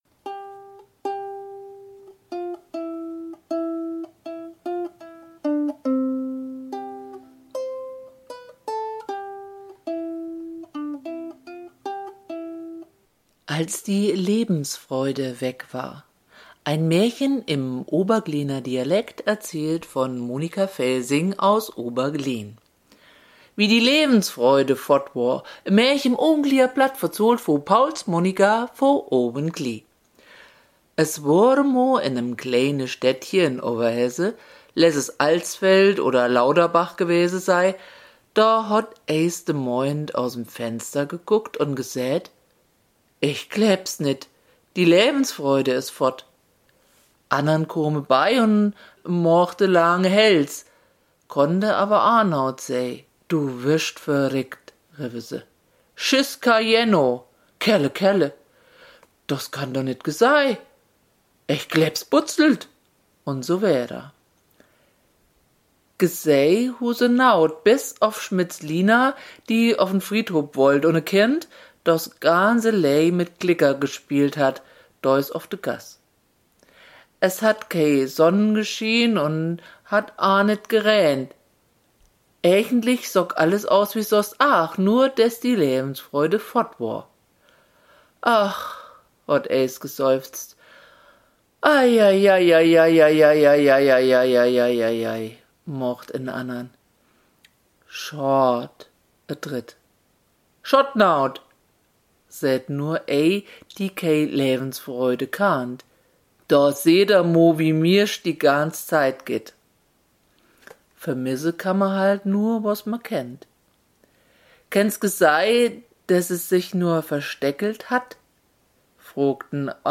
Wie-die-Läwensfroide-foadd-woar-mit-Glockenspiel-online-audio-converter.com_.mp3